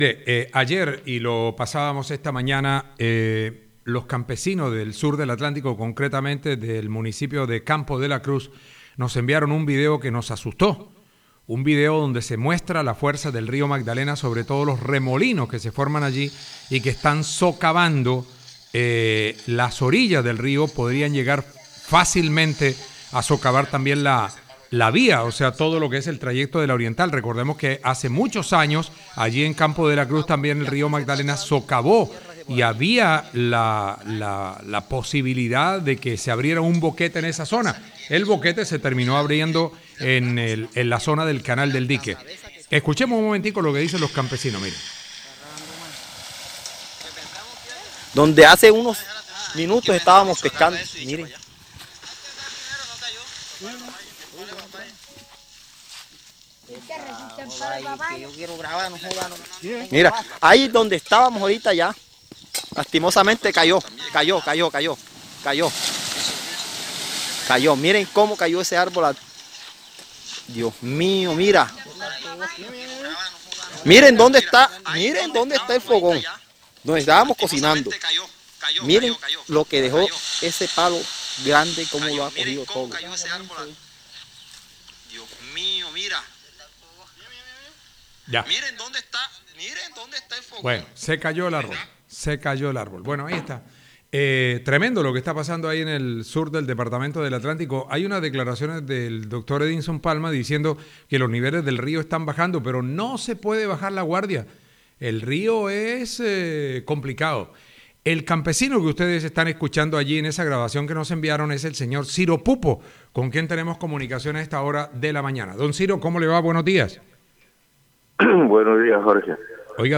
labriego